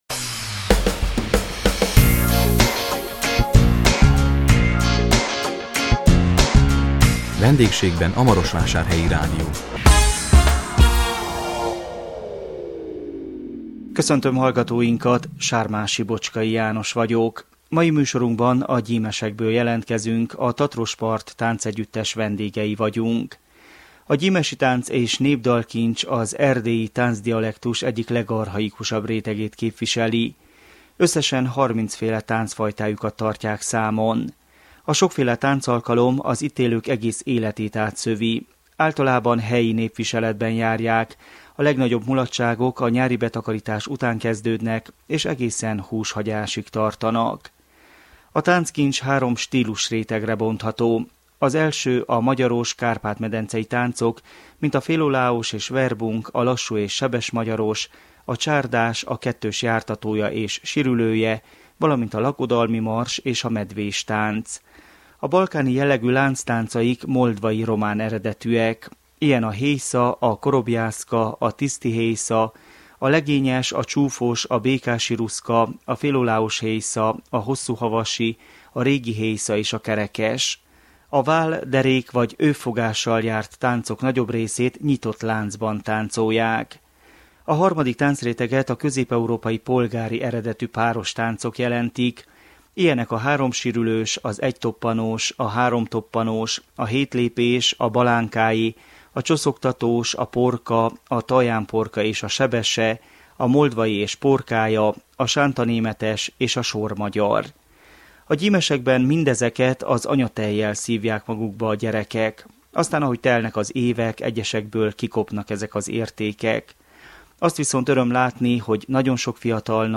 A 2017 november 30-án jelentkező Vendégségben a Marosvásárhelyi Rádió című műsorunkban a Gyimesekből jelentkeztünk, a Tatrospart Táncegyüttes vendégei voltunk. A gyimesi tánc- és népdalkincs az erdélyi táncdialektus egyik legarchaikusabb rétegét képviseli.